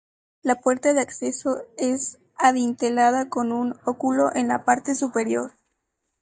Read more Meaning oculus (round or oval window) Concepts oculus Translations oculus Frequency A2 Hyphenated as ó‧cu‧lo Pronounced as (IPA) /ˈokulo/ Etymology Borrowed from Latin oculus In summary Borrowed from Latin oculus.